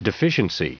Prononciation du mot deficiency en anglais (fichier audio)
Prononciation du mot : deficiency